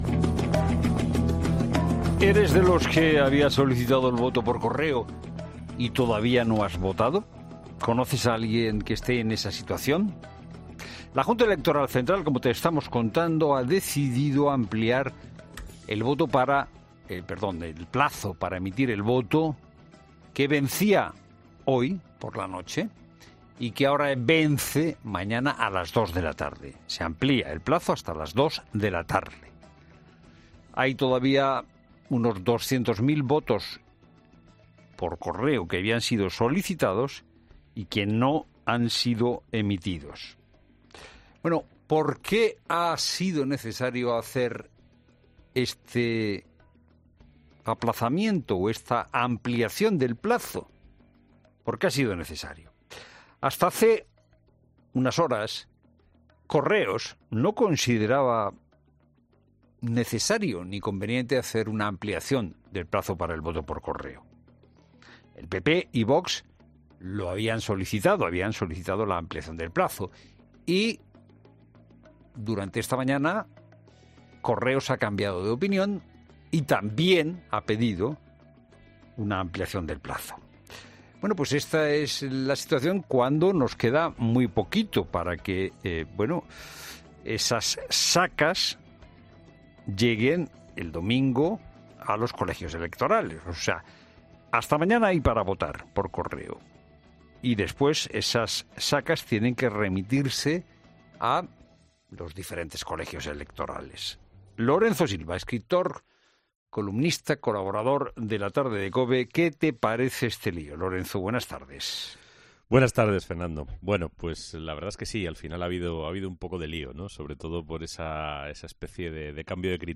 Esta tarde, ha vuelto a los micrófonos de COPE para valorar la prórroga.